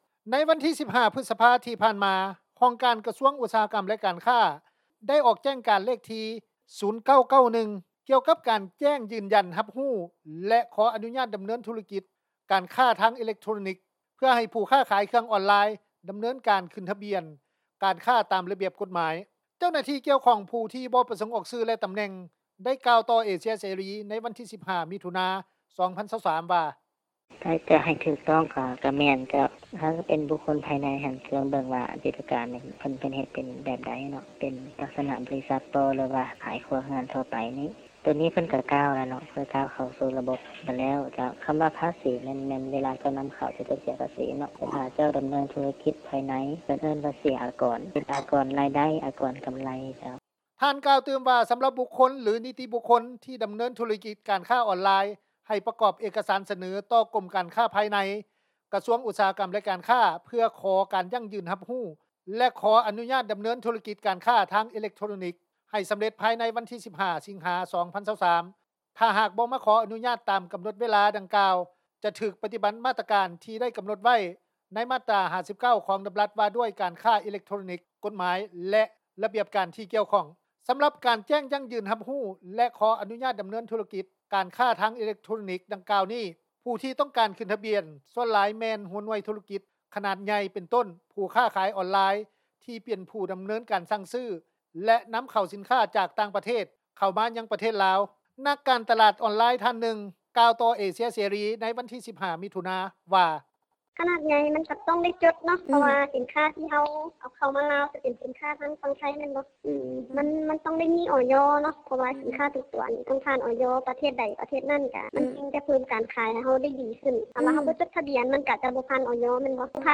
ເຈົ້າໜ້າທີ່ກ່ຽວຂ້ອງ ຜູ້ທີ່ບໍ່ປະສົງອອກຊື່ ແລະ ຕໍາແໜ່ງ ໄດ້ກ່າວຕໍ່ວິທຍຸ ເອເຊັຽເສຣີ ໃນວັນທີ 15 ມິຖຸນາ 2023 ວ່າ: